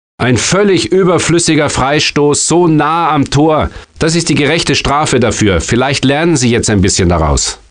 Premiere commentator and presenter Fritz von Thurn und Taxis was again employed for the live commentary. As before, PC and PlayStation fans will enjoy a TV-like stadium atmosphere.
Kommentator: